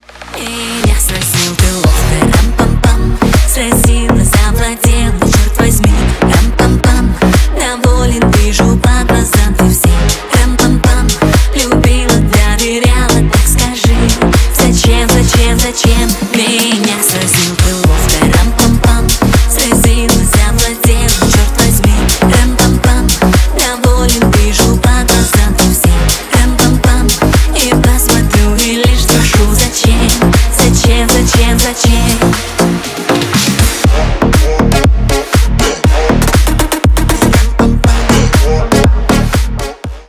Танцевальные
клубные # кавер